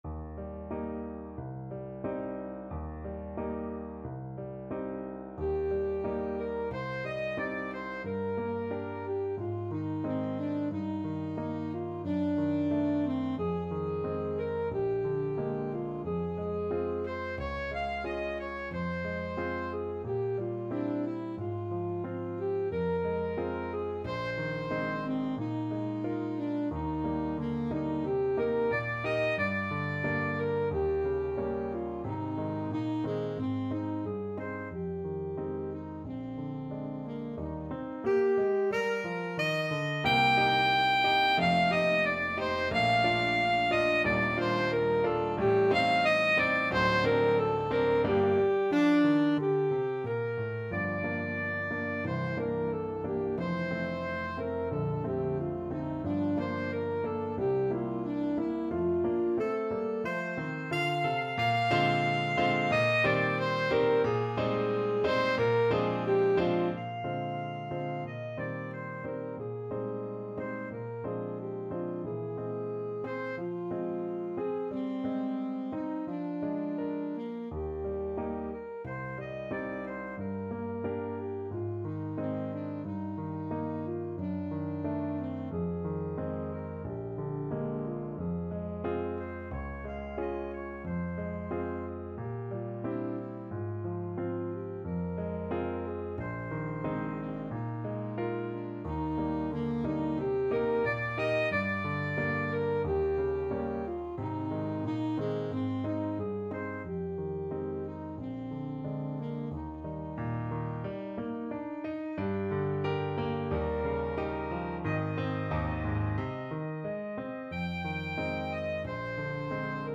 Classical Bridge, Frank Spring Song, H.104. No.2 Alto Saxophone version
Alto Saxophone
Eb major (Sounding Pitch) C major (Alto Saxophone in Eb) (View more Eb major Music for Saxophone )
Ab4-G6
2/4 (View more 2/4 Music)
~ = 100 Allegretto con moto =90
Classical (View more Classical Saxophone Music)